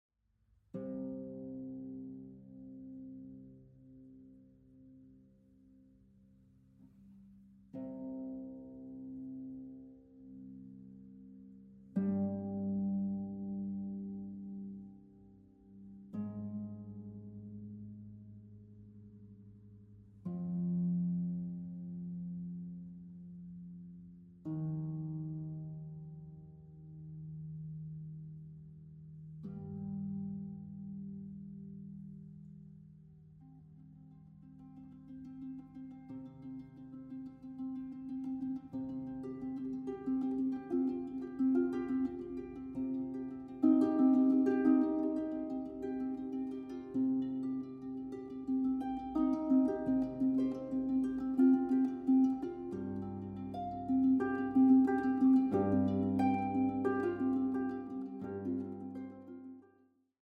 Contemporary Music for Harp
Harp